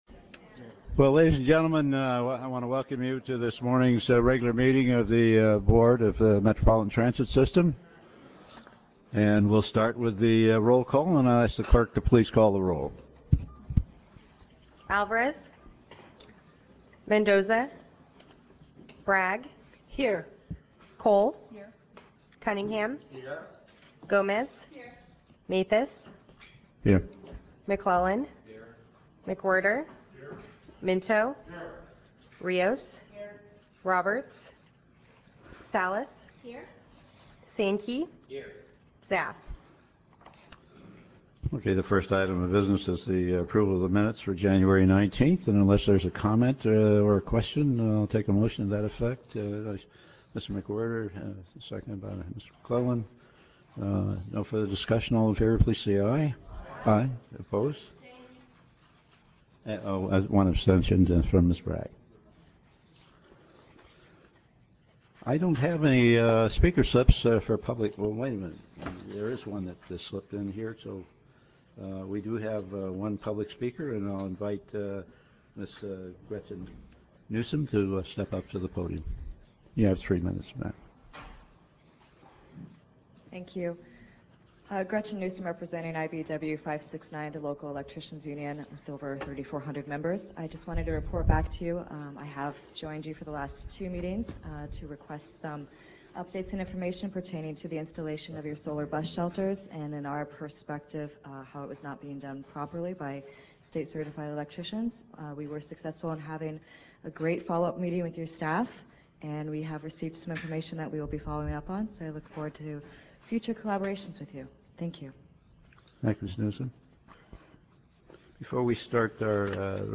Meeting Type Board Meeting